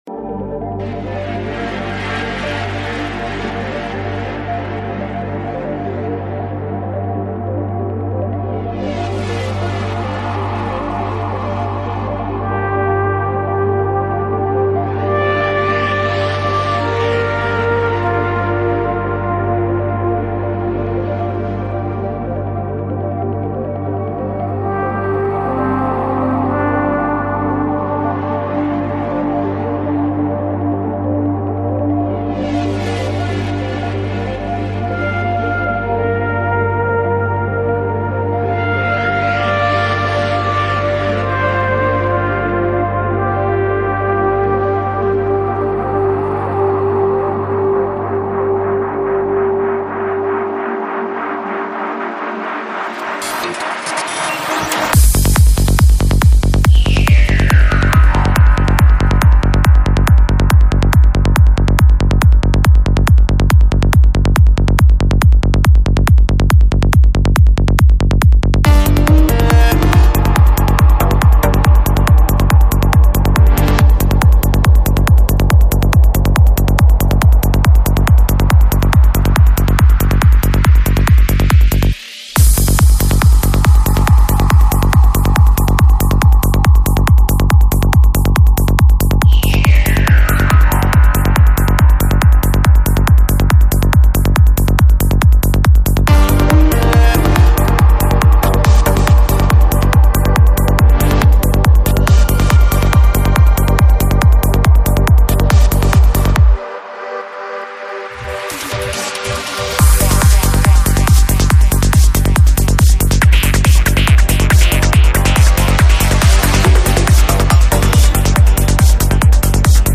Жанр: Electronic
Psy-Trance